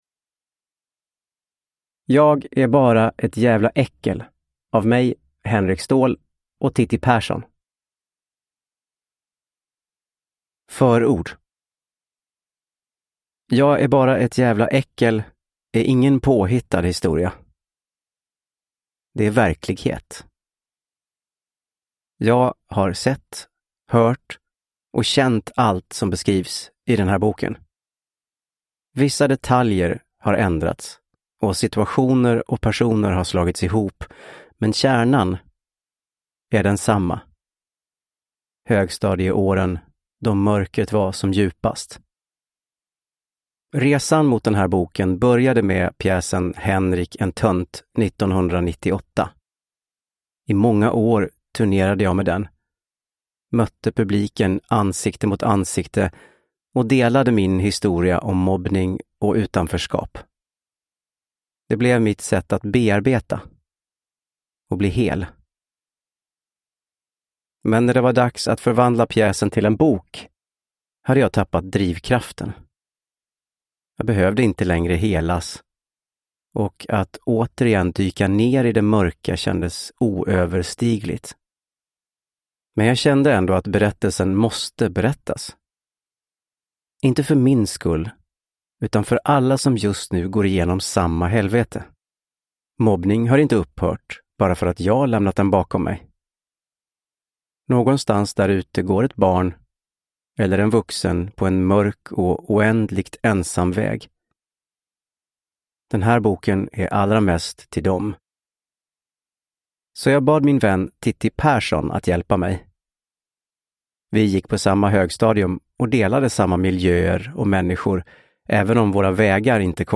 Jag är bara ett jävla äckel – Ljudbok